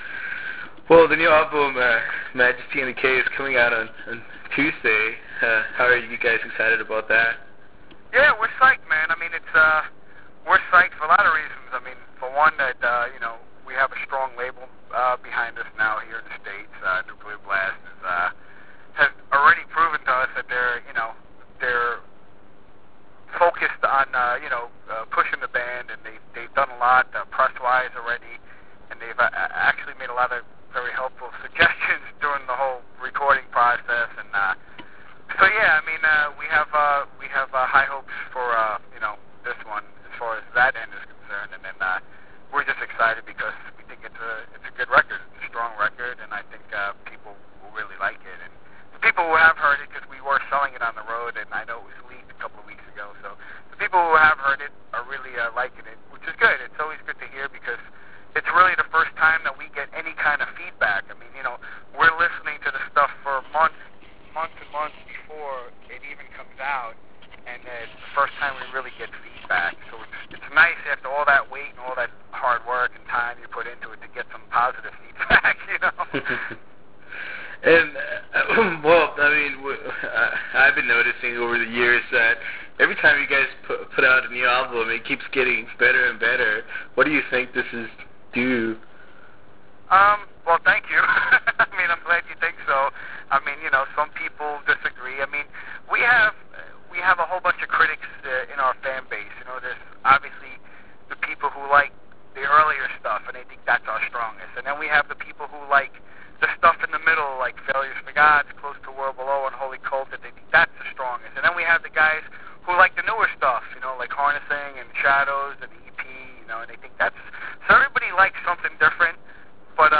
We had a phone interview